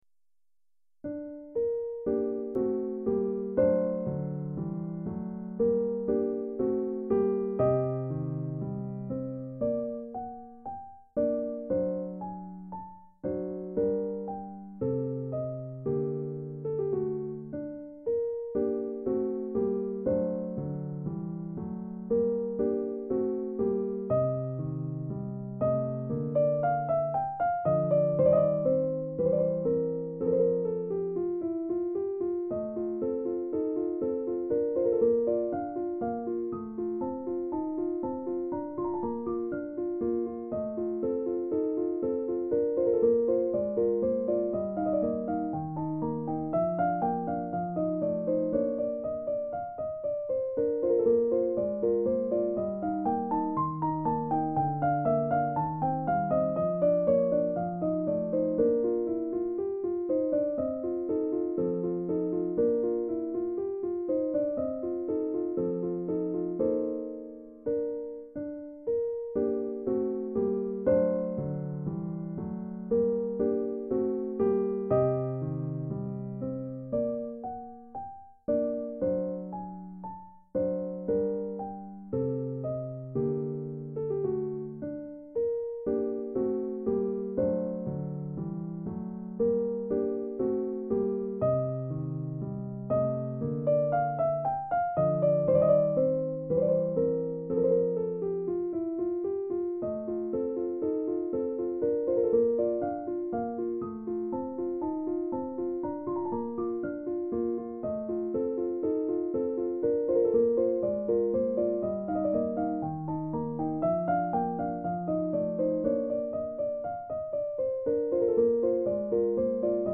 This has only two parts (Andante and Rondo), so it’s not actually a sonata.
The midi file was downloaded from The Classical Music Archives.